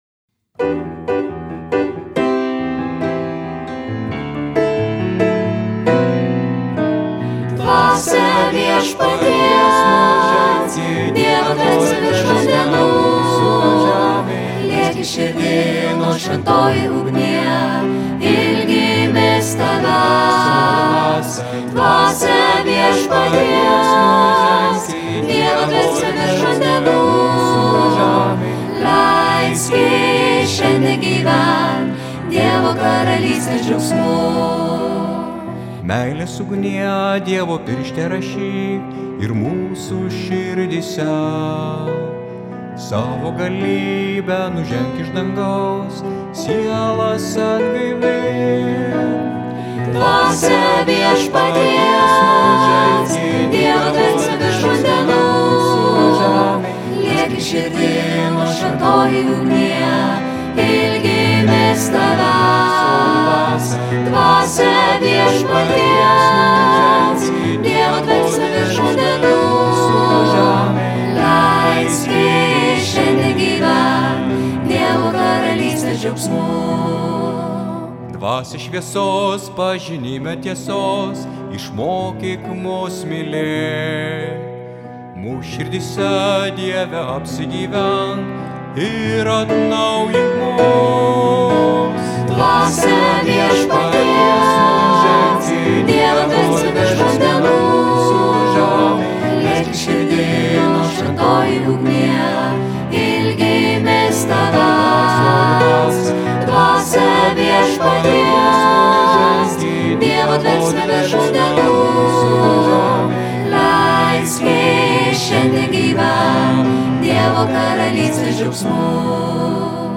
Choras: